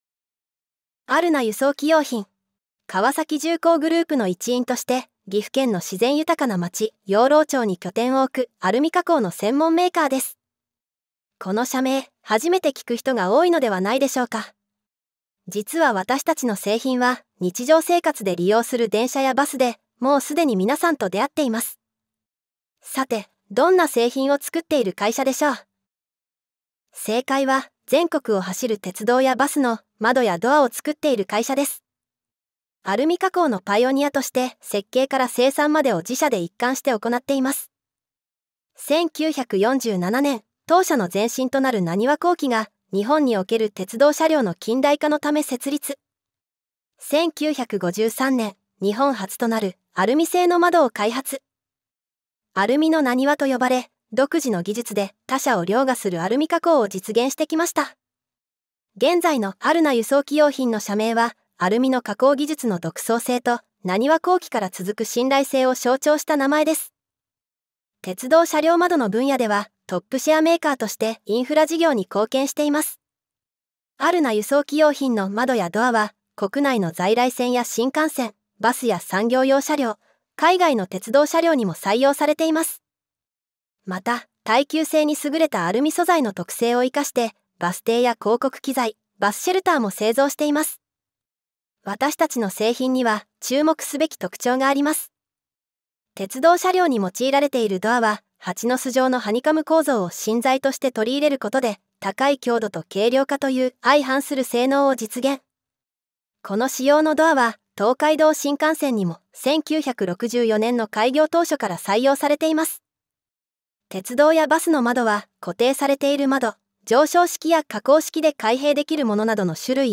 女性の声